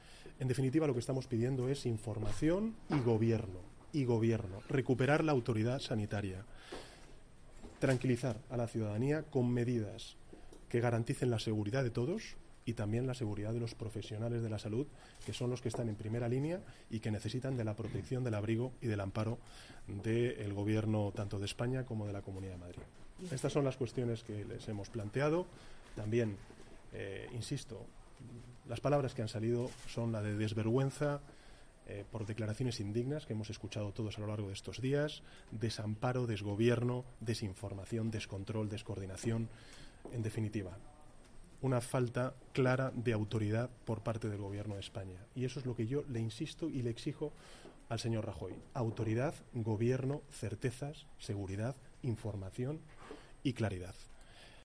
Declaraciones de Pedro Sánchez tras reunirse con los profesionales de la sanidad madrileña en el Congreso 10/10/2014